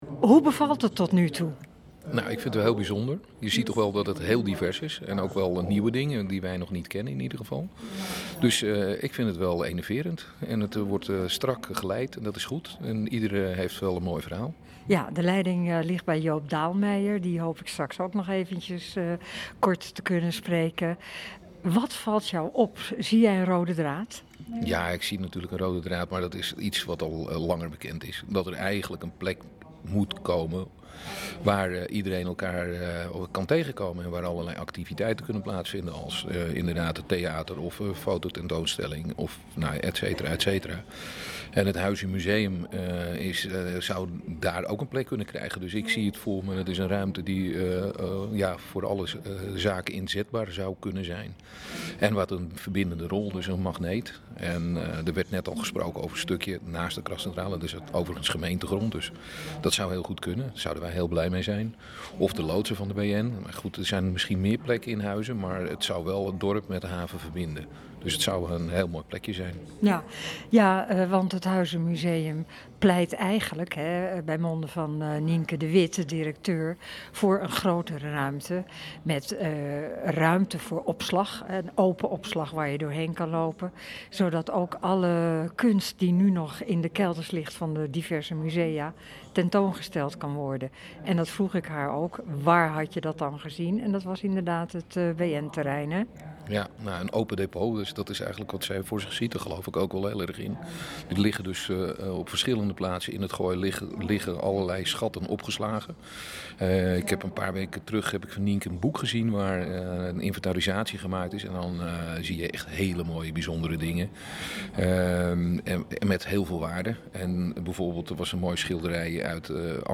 Ruim 20 organisaties mochten in Huizen hun woordje doen tijdens het raadspodium over Kunst en Cultuur.